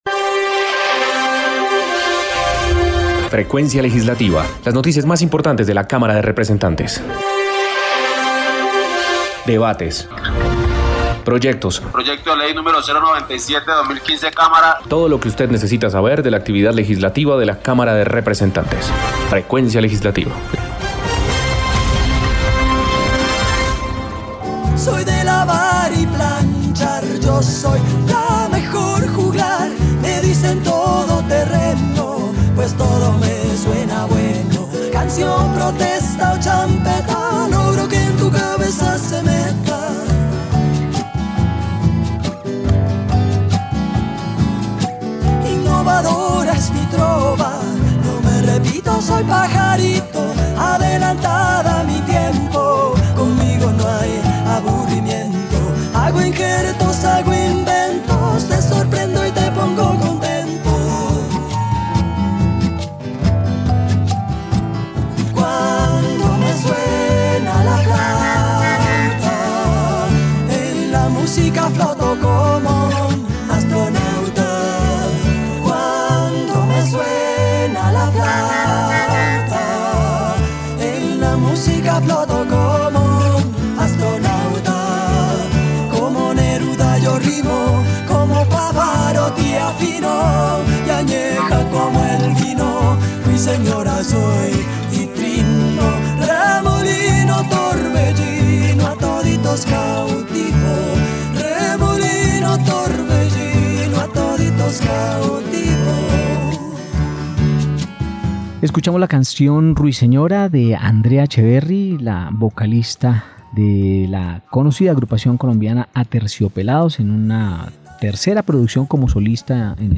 Programa Radial Frecuencia Legislativa. Domingo 5 de Septiembre de 2021